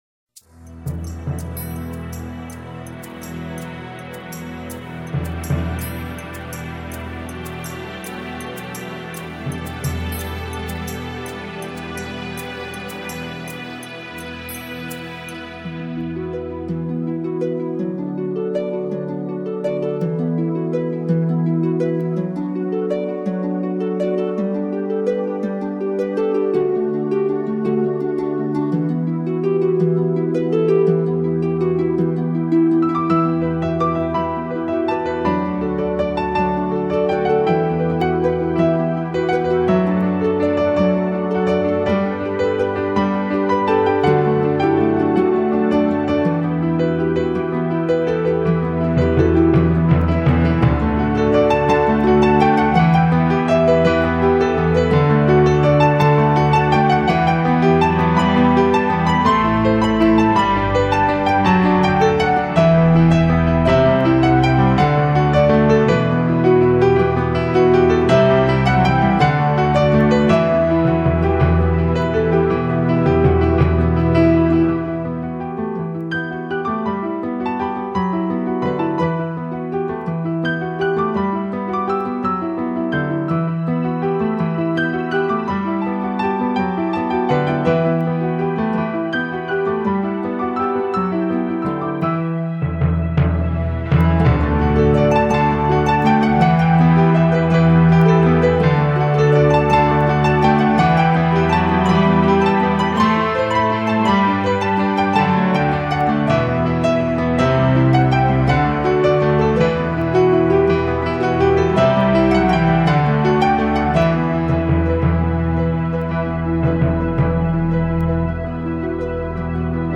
戏剧感，他以世间多情男子对月亮的悟性，琢磨出钢琴静谧、激情的双面美